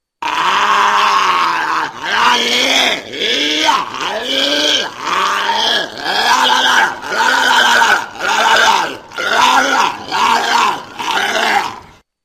Звуки моржей
В подборке представлены характерные аудиозаписи: мощный рев и коммуникационные сигналы этих удивительных животных.